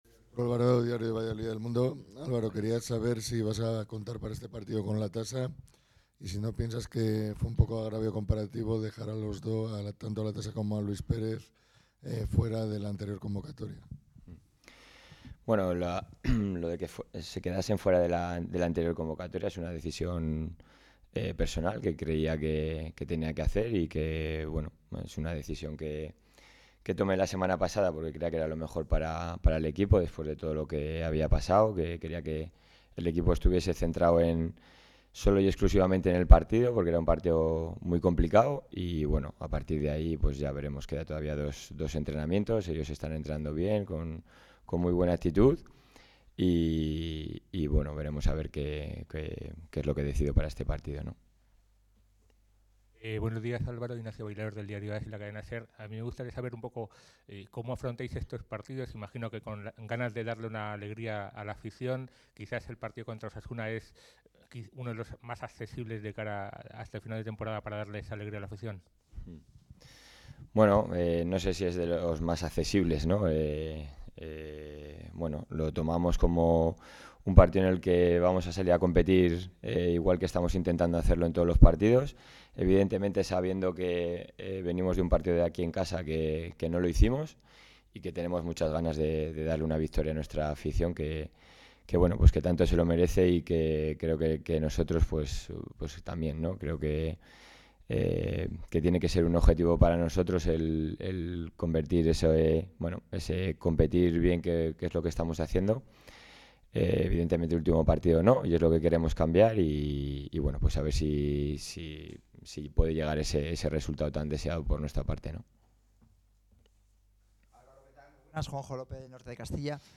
aquí la rueda de prensa completa